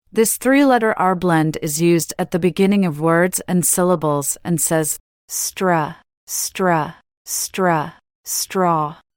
This three-letter R-blend is used at the beginning of words and syllables and says: /str/, /str/, /str/, straw.
STR-straw-lesson-AI.mp3